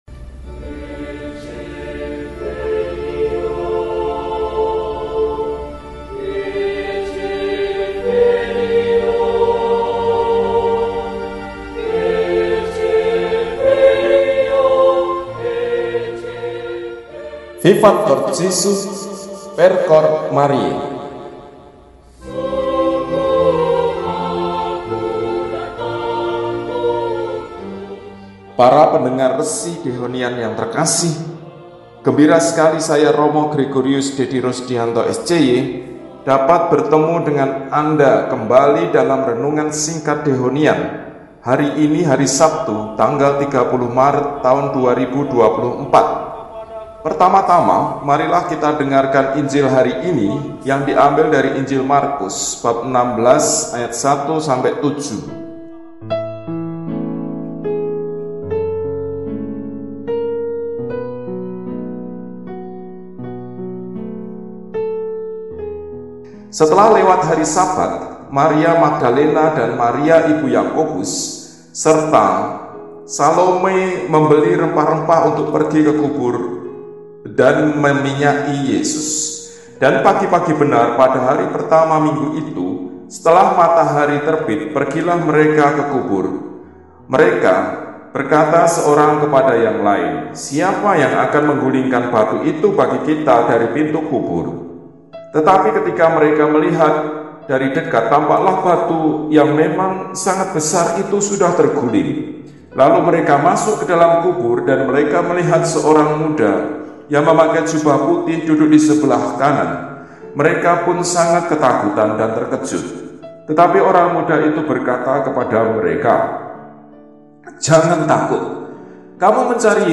Sabtu Malam, 30 Maret 2024 – Vigili Paskah (Malam Paskah – Tirakatan Kebangkitan Tuhan) – RESI (Renungan Singkat) DEHONIAN